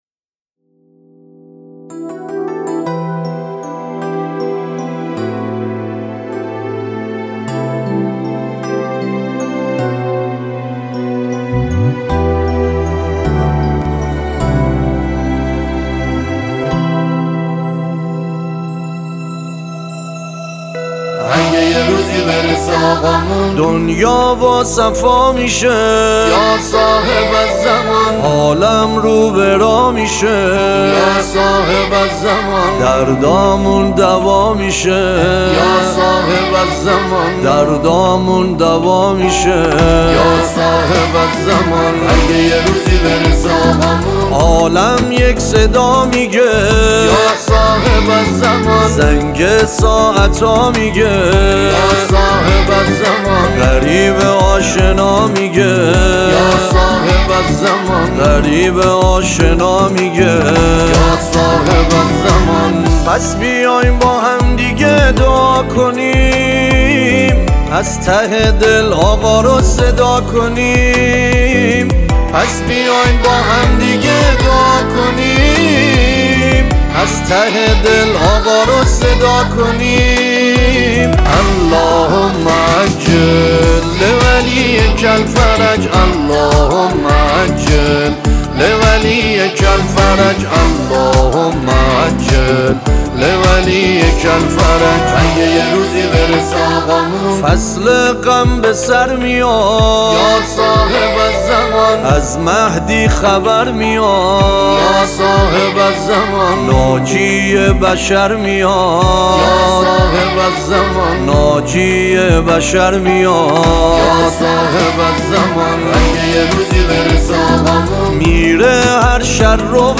نماهنگ احساسی و دلنشین